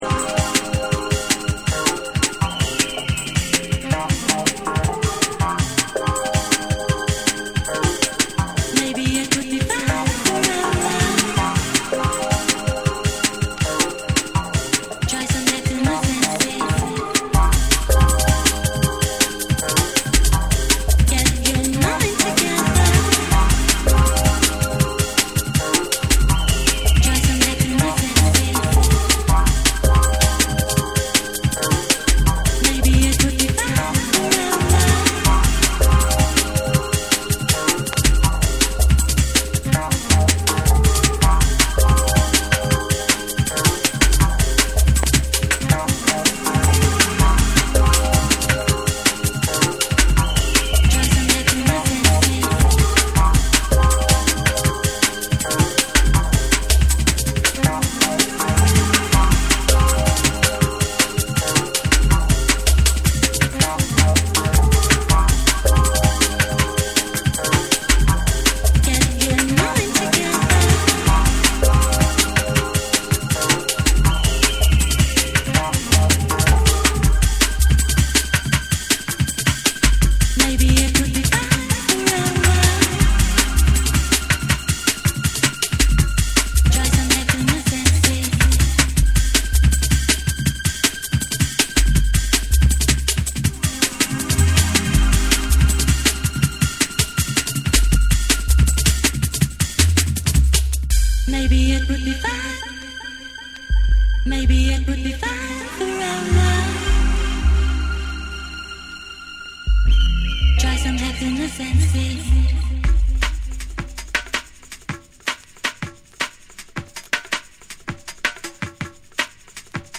浮遊感のあるシンセとリズミカルなベースラインが心地よく絡み合い、アトモスフェリックなドラムンベースを披露する
JUNGLE & DRUM'N BASS